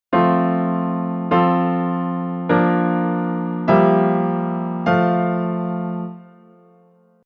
And again the reduction:
Pretty dull.